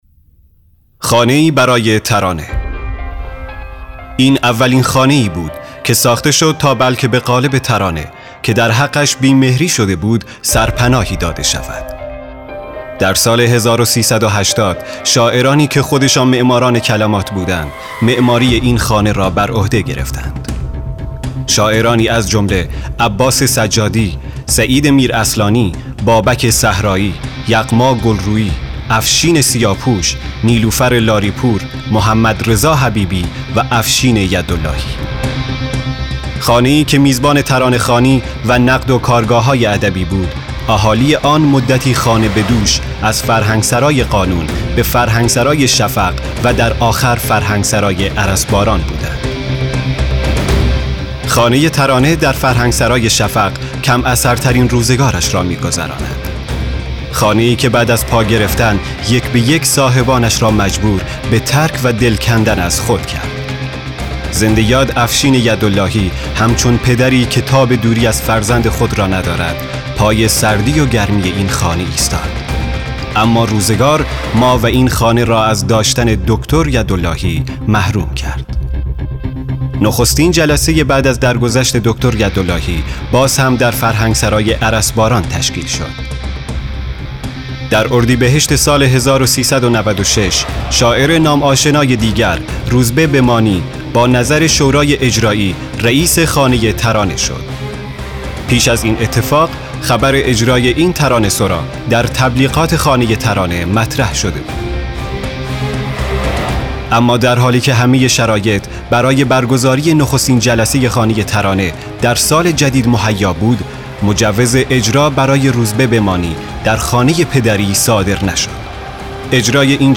ژانر : چالشی و تحلیلی